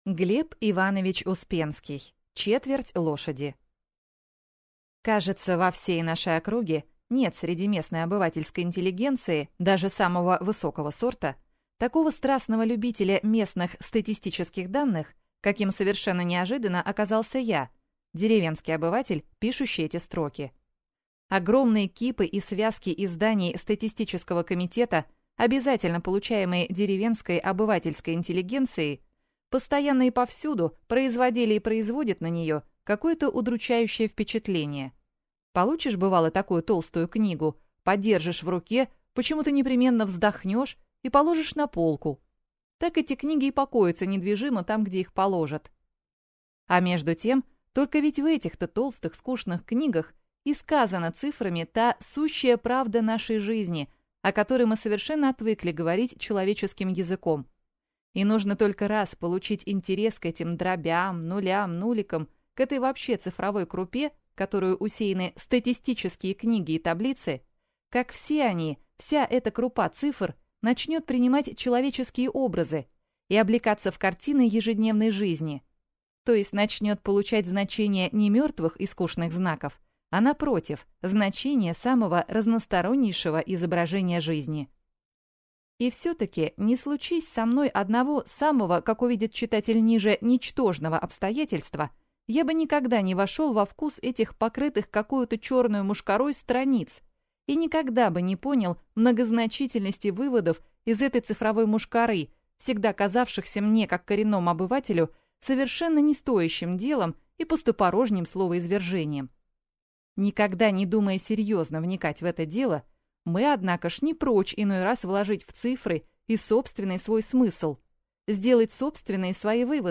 Аудиокнига «Четверть» лошади | Библиотека аудиокниг